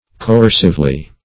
coercively - definition of coercively - synonyms, pronunciation, spelling from Free Dictionary
Co*er"cive*ly, adv.